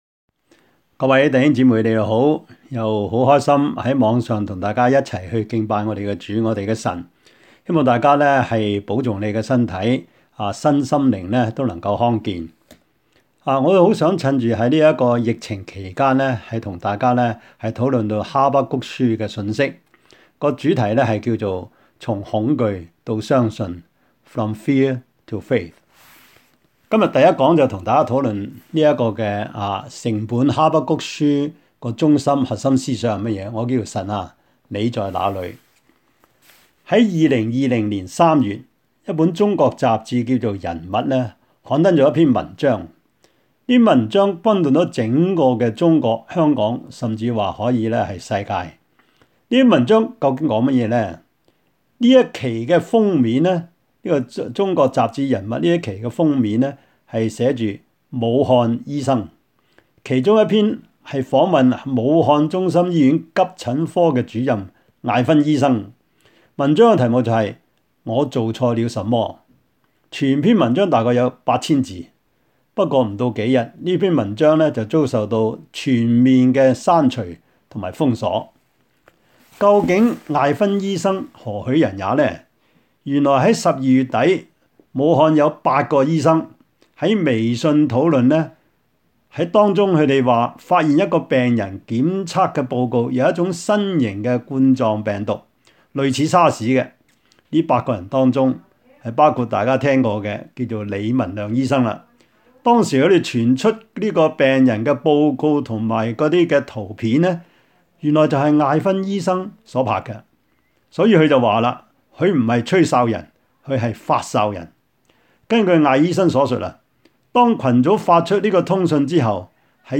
疫症中之哈巴谷書講道系列
Habakkuk-Sermon-1.mp3